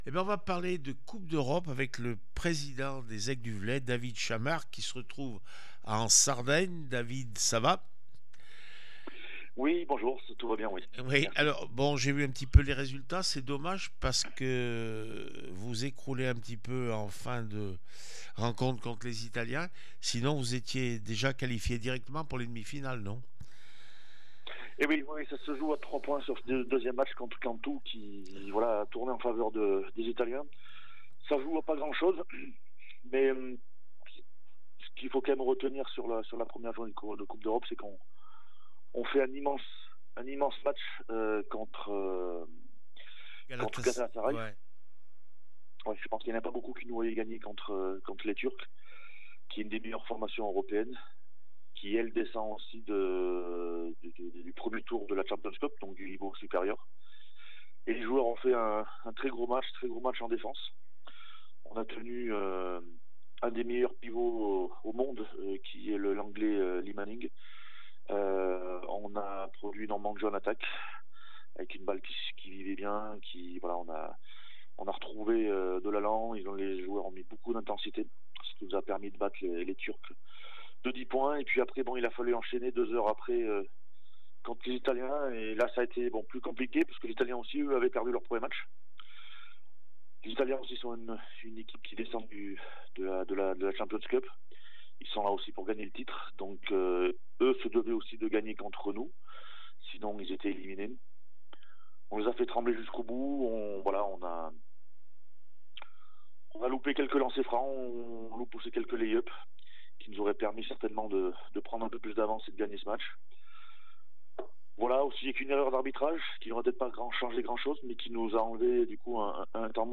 26 avril 2025   1 - Sport, 1 - Vos interviews